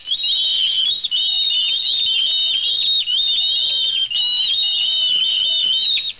Mice: